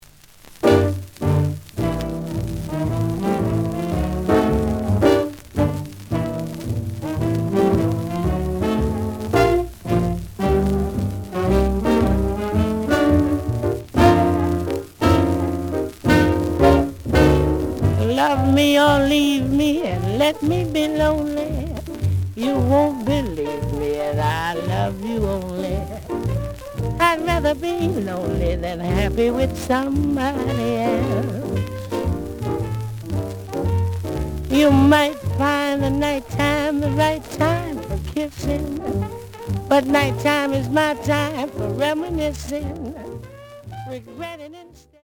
The audio sample is recorded from the actual item.
●Genre: Vocal Jazz
Looks good, but slight noise on both sides.)